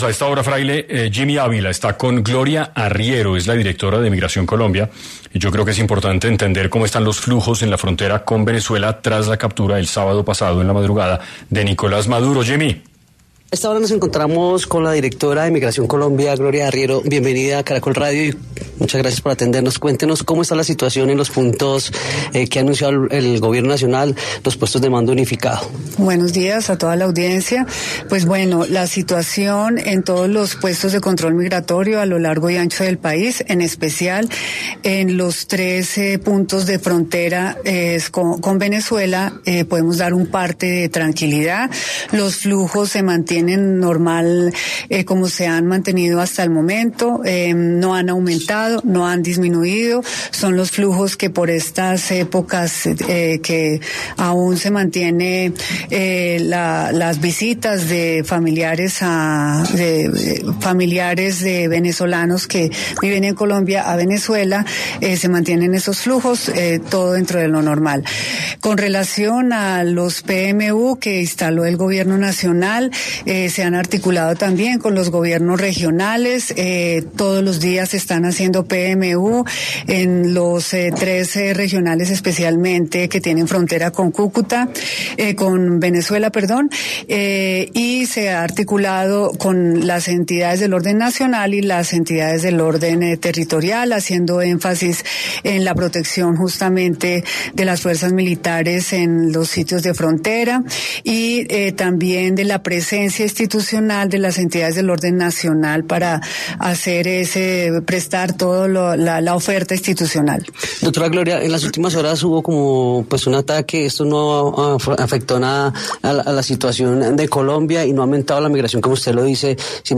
Por los micrófonos de 6AM de Caracol Radio pasó Gloria Esperanza Arriero López, directora de Migración Colombia, quien dio un detallado panorama del estado actual del paso fronterizo entre ambos países, luego de la incertidumbre institucional desatada en Venezuela.